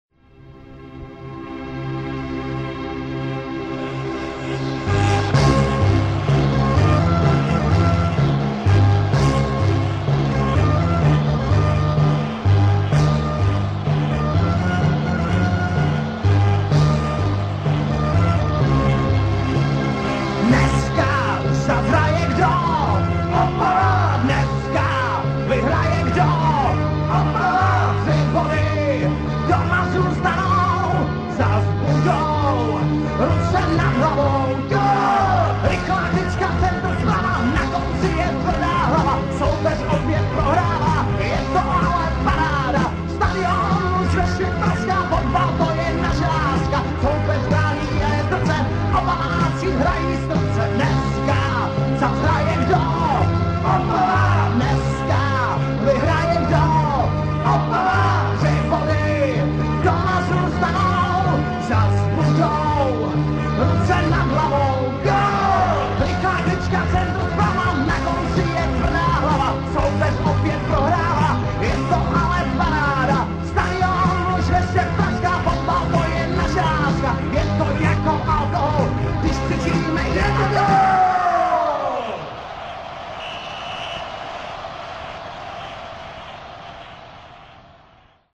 Znělka
Předzápasová znělka Slezského FC ke stažení ve formátu .mp3